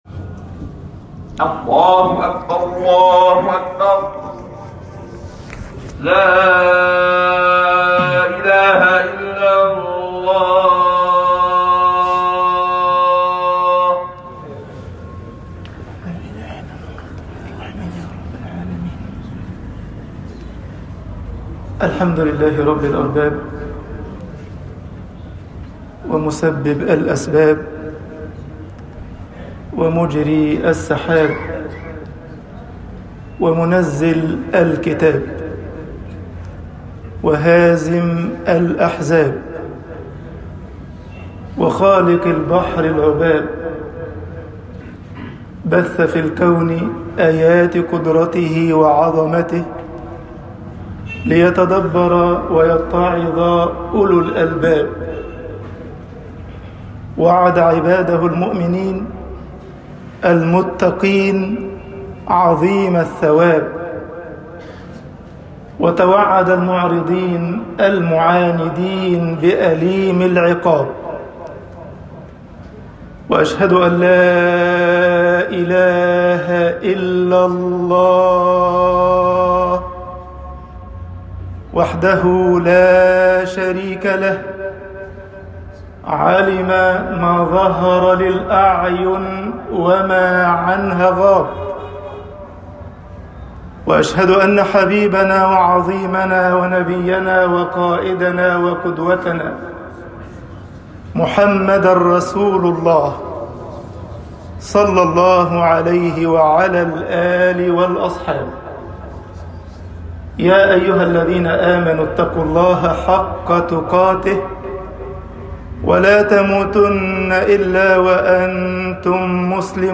خطب الجمعة - مصر